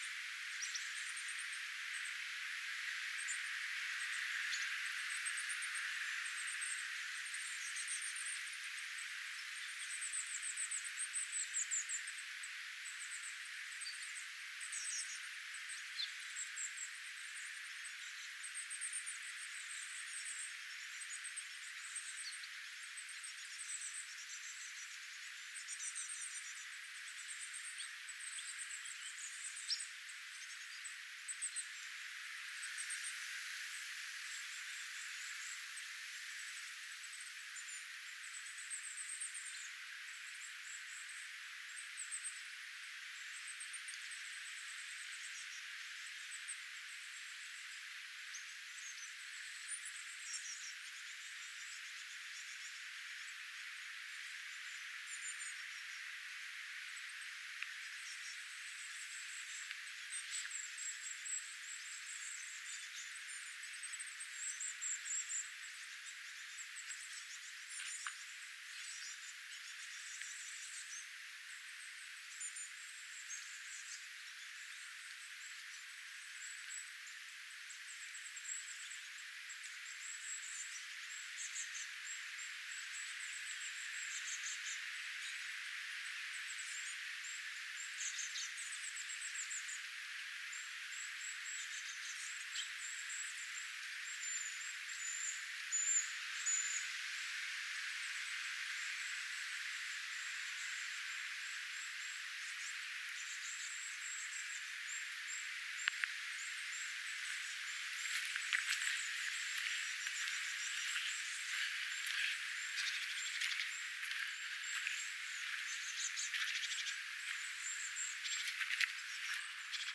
pikkulintuparvi
pikkulintuparvi.mp3